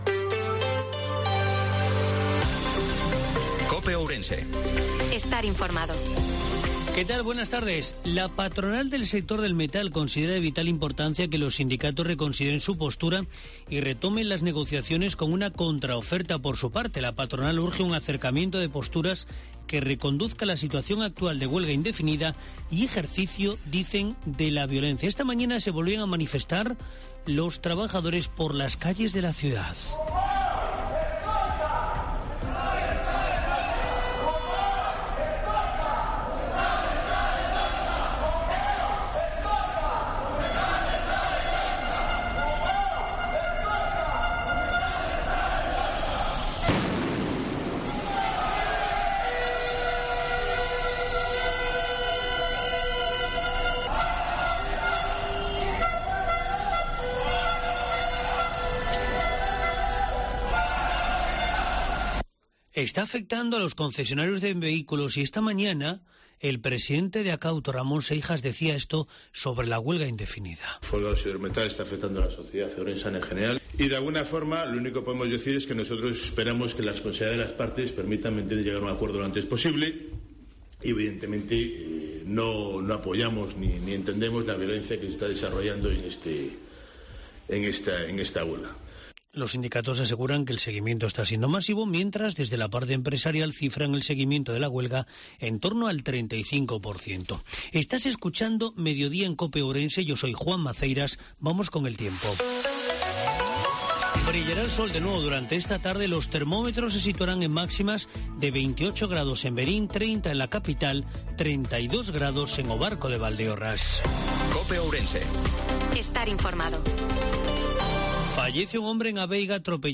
INFORMATIVO MEDIODIA COPE OURENSE-06/10/2022